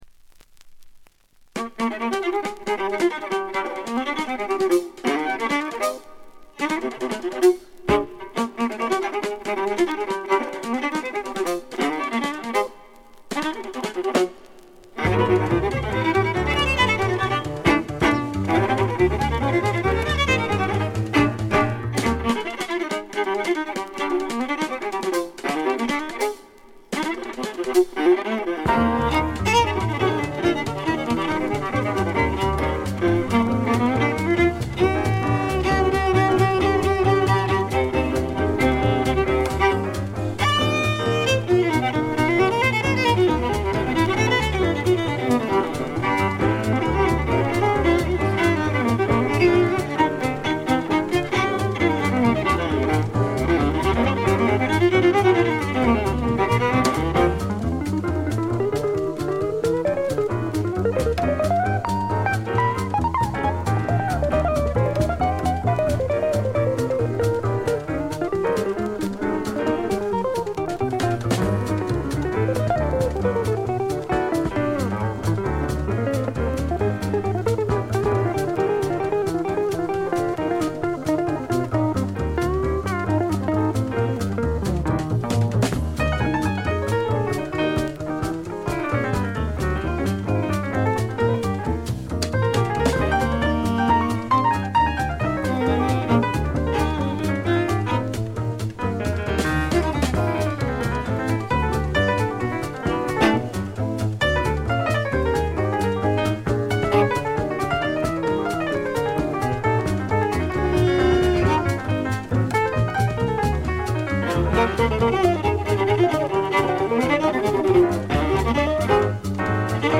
部分試聴ですがほとんどノイズ感無し。
エリア・コード615等で活躍した名フィドラ-。
試聴曲は現品からの取り込み音源です。
Violin, Viola, Mandola